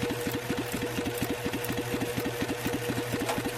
machine_idle_1star_01.ogg